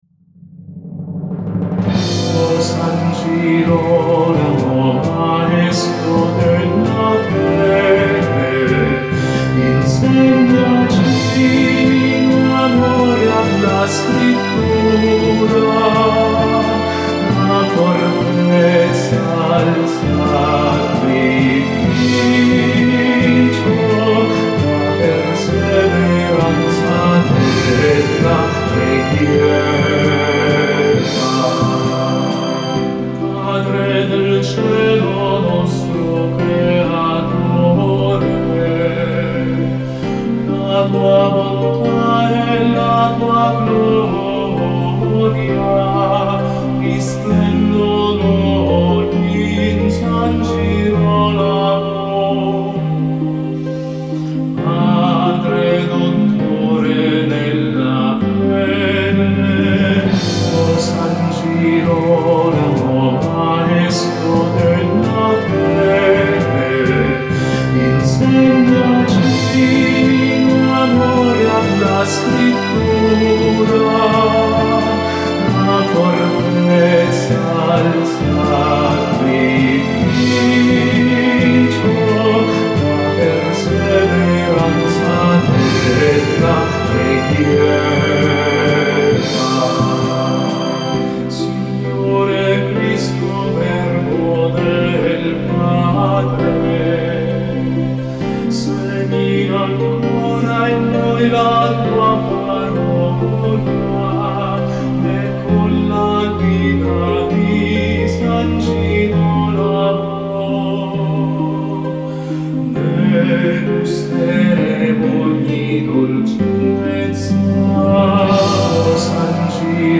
inno-san-girolamo2.mp3